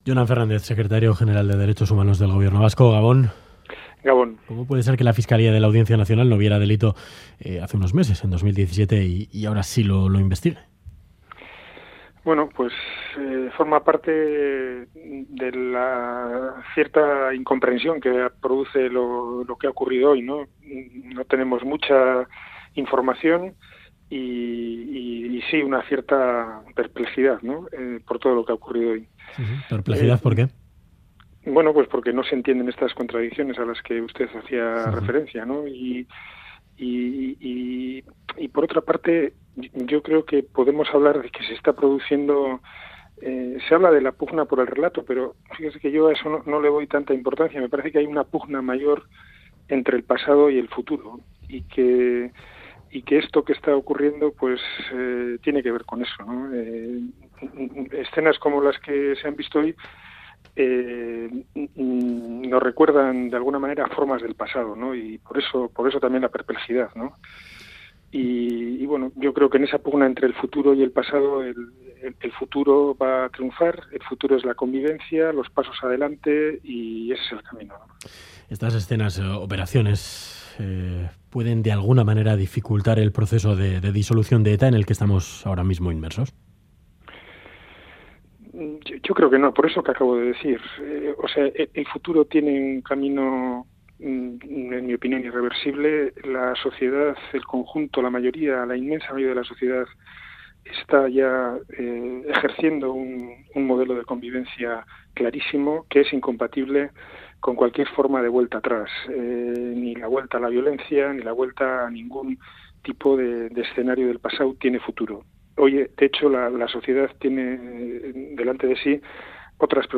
Radio Euskadi GANBARA 'Los actos de recibimiento forman parte del pasado' Última actualización: 20/03/2018 22:39 (UTC+1) Entrevista en 'Ganbara' a Jonan Fernández, Secretario General de Derechos Humanos del Gobierno Vasco.